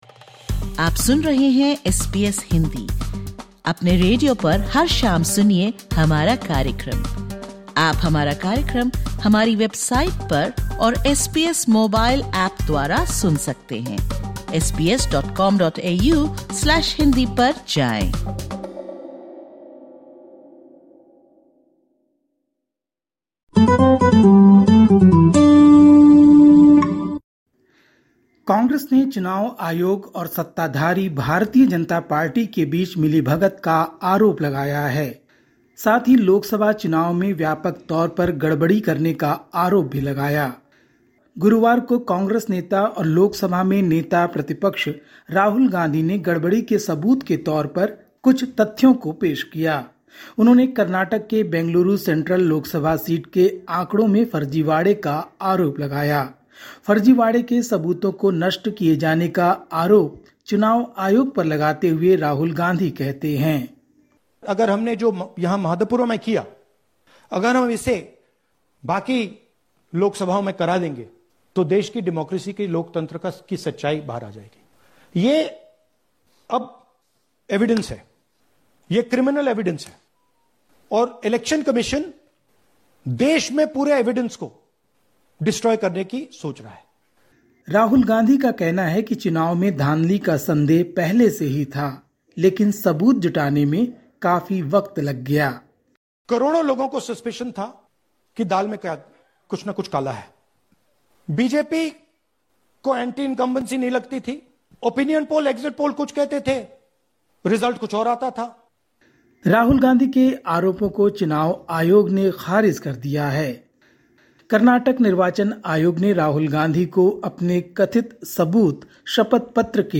Listen to the latest SBS Hindi news from India. 8/08/25